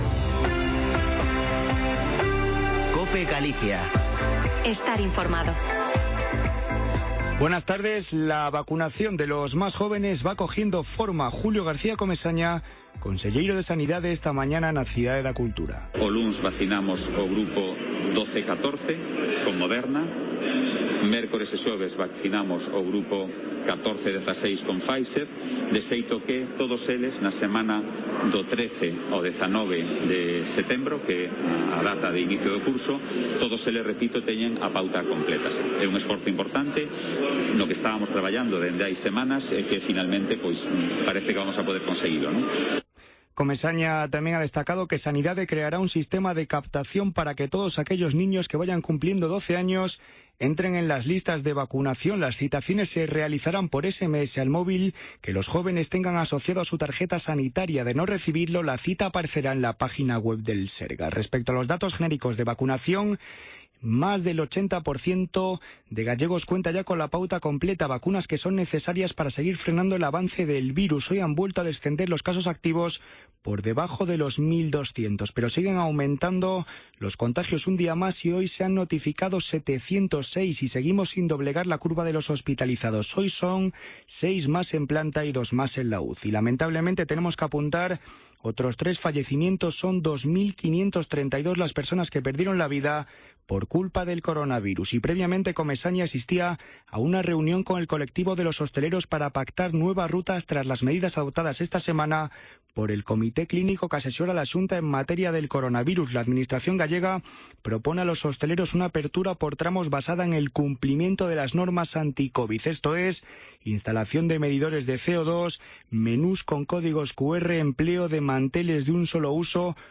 Informativo Mediodía en Cope Galicia 20/08/2021. De 14.48 a 14.58h